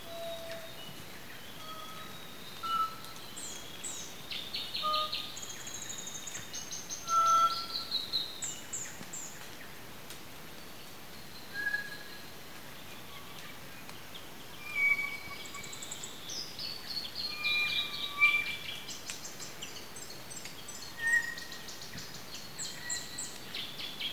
North Island Kokako (Callaeas wilsoni)
Location or protected area: Kaharoa Conservacion Area
Condition: Wild
Certainty: Recorded vocal
7-10-KOKAKO-KOKAKO.mp3